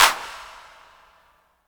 Clap 6.wav